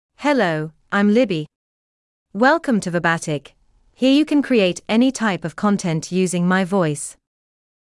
Libby — Female English (United Kingdom) AI Voice | TTS, Voice Cloning & Video | Verbatik AI
FemaleEnglish (United Kingdom)
Voice sample
Female
Libby delivers clear pronunciation with authentic United Kingdom English intonation, making your content sound professionally produced.